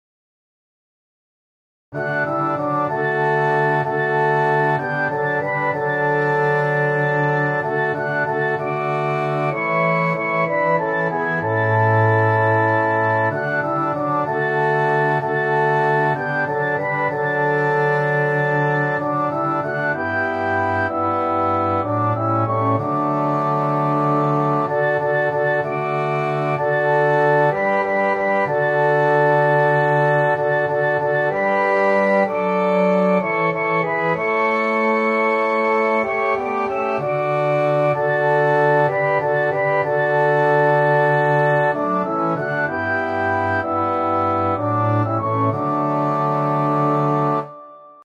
Hymns of praise